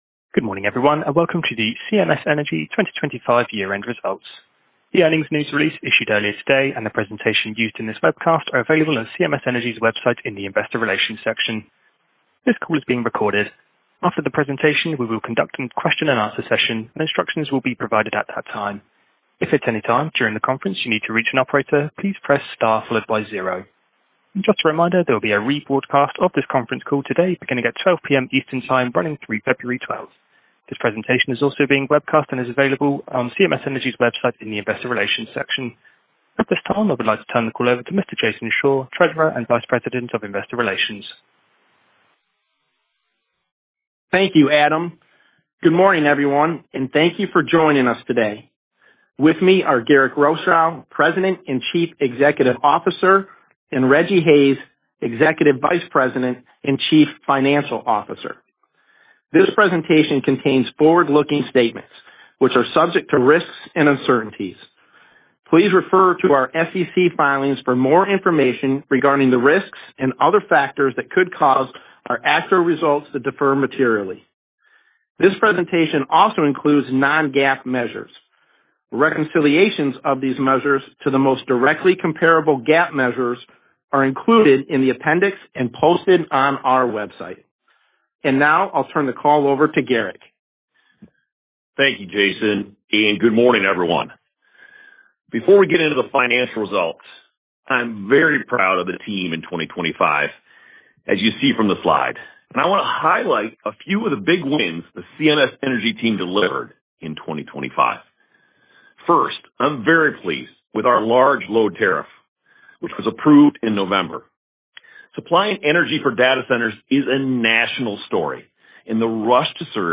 CMS-Energy-YE-2025-Earnings-Call.mp3